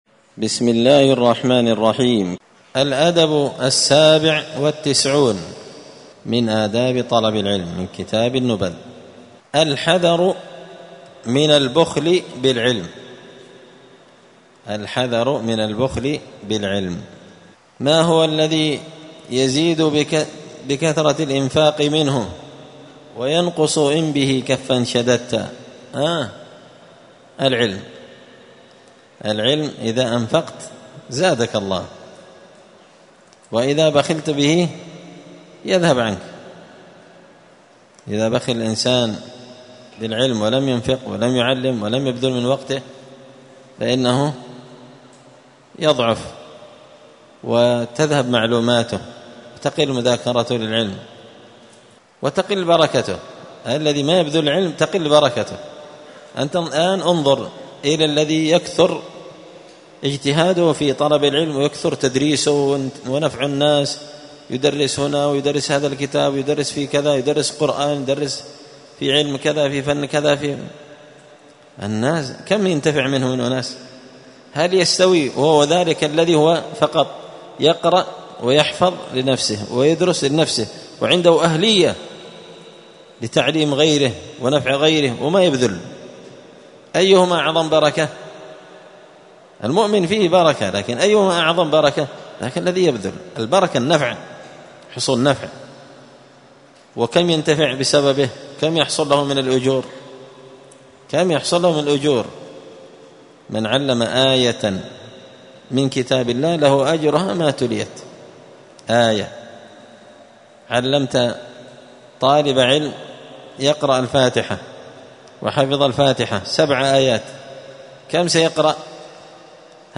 الخميس 11 ربيع الثاني 1445 هــــ | الدروس، النبذ في آداب طالب العلم، دروس الآداب | شارك بتعليقك | 108 المشاهدات